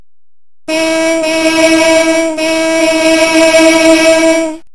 USBにつないだ瞬間、あるいはプッシュボタンを押したり、USBからデータを送るたびに圧電サウンダから「へぇ〜」と脱力感のある音が鳴ります。
※圧電素子ではなく、PCのライン入力から取り込んだ音声です。